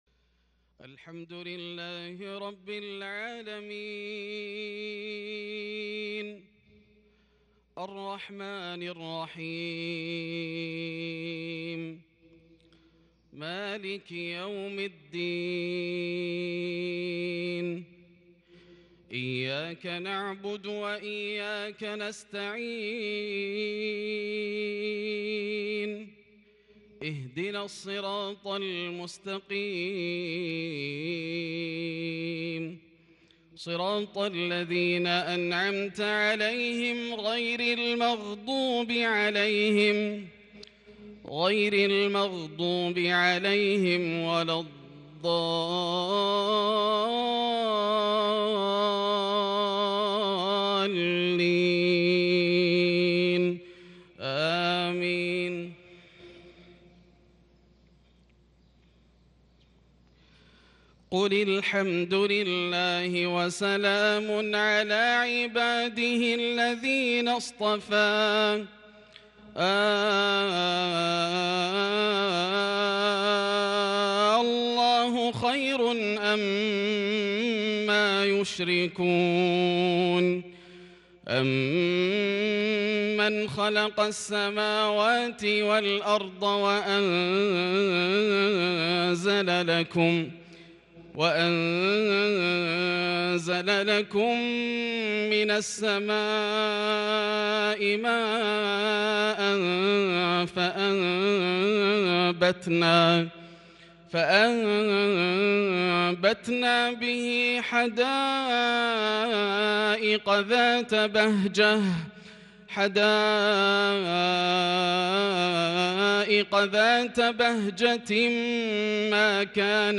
صلاة العشاء للشيخ ياسر الدوسري 28 جمادي الأول 1442 هـ
تِلَاوَات الْحَرَمَيْن .